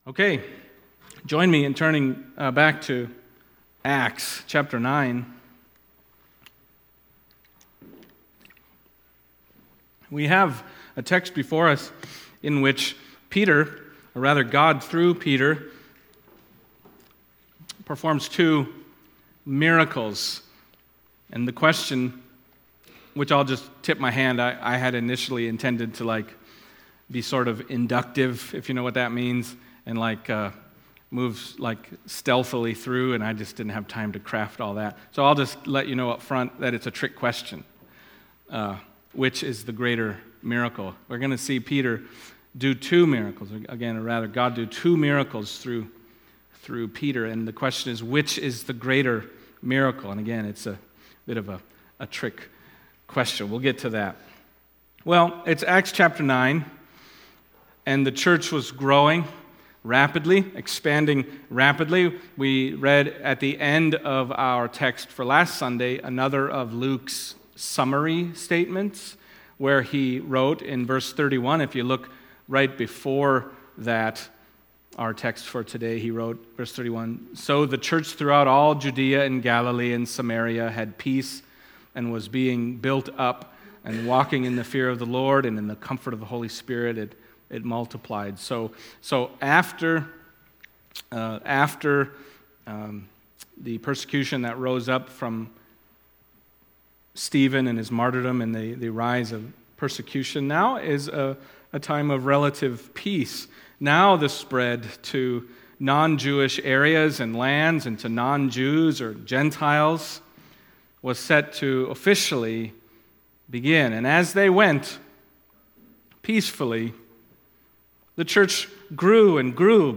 Acts Passage: Acts 9:32-43 Service Type: Sunday Morning Acts 9:32-43 « Persecutor Turned Preacher And Also to the Gentiles